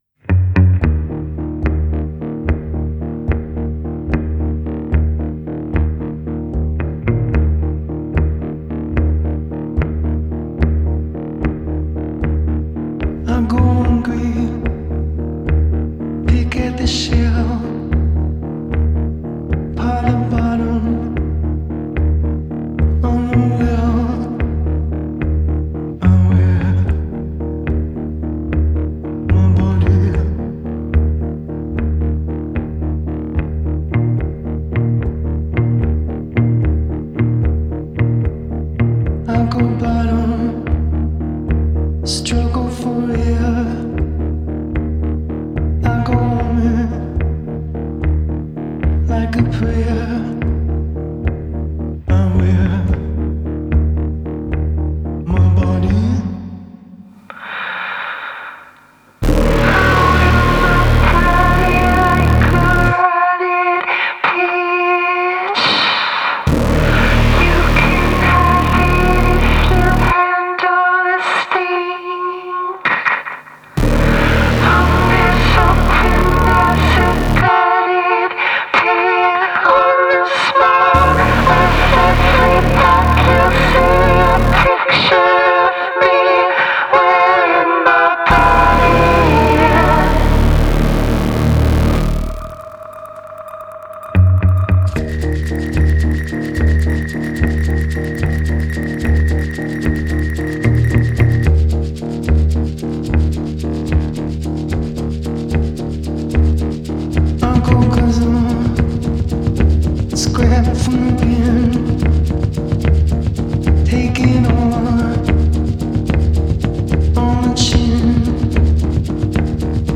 • Жанр: Альтернатива